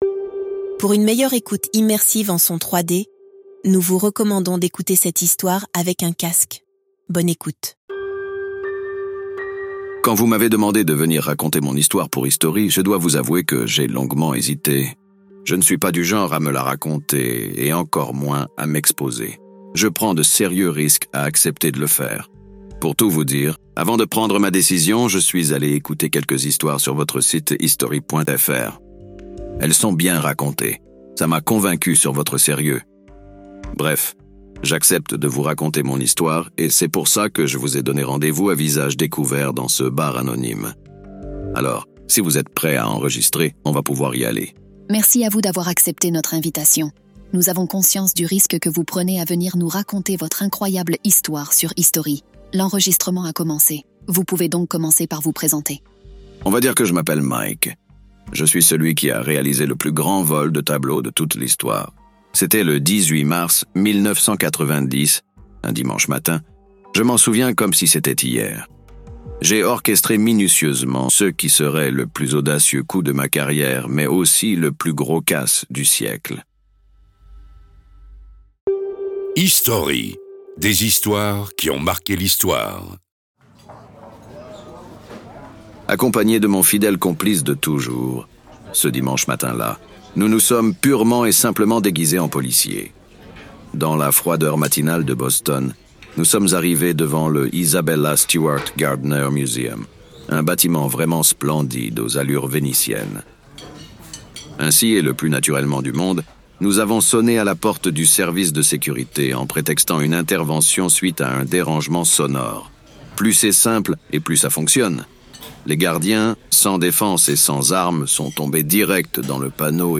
Dans cet épisode, nous avons rendez-vous dans un bar obscur pour parler du plus gros casse du siècle qui a eu lieu à Boston en 1990.